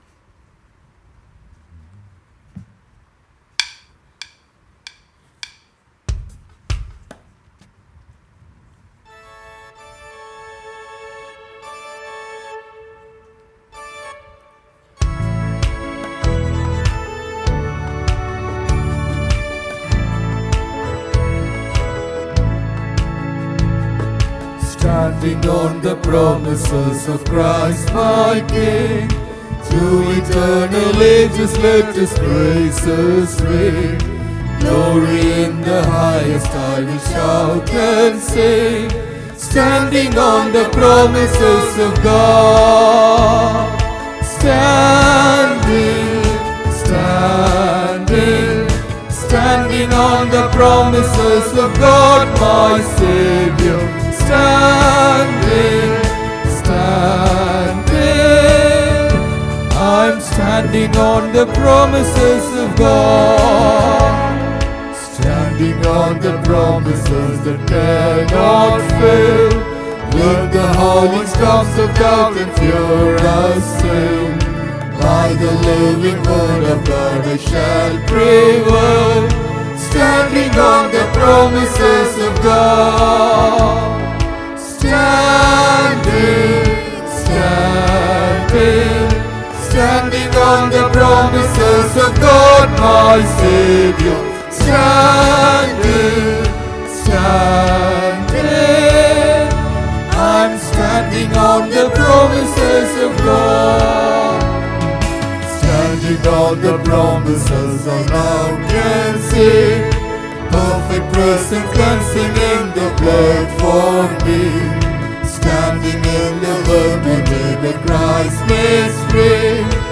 20 August 2023 Sunday Morning Service – Christ King Faith Mission